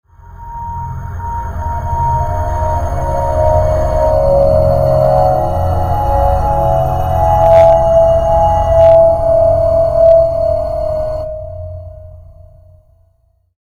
Here's the same background track with a Convolution effect.